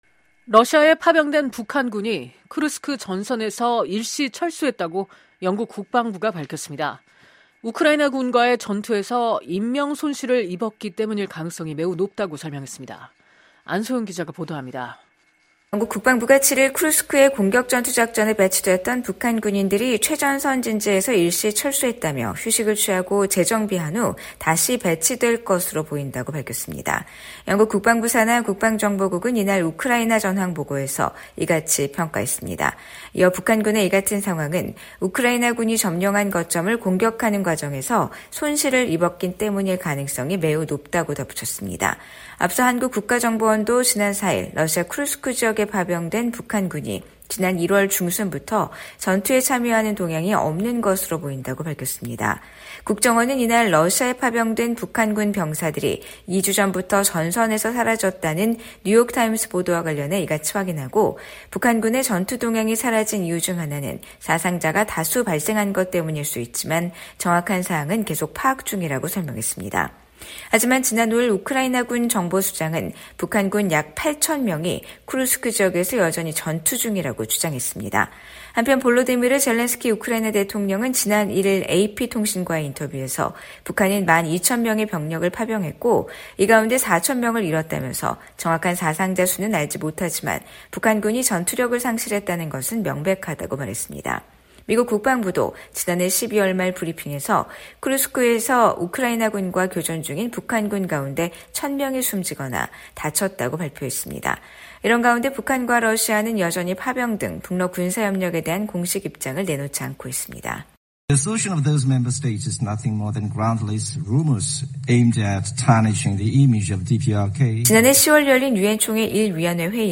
속보